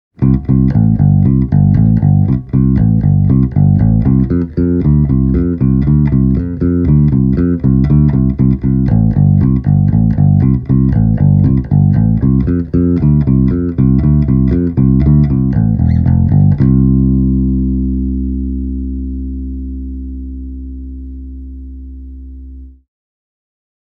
Let’s start with a nice and clean Pop tone:
Clean Pop